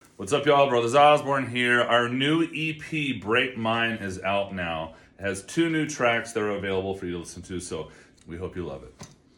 LINER-Brothers-Osborne-EP-available-now.mp3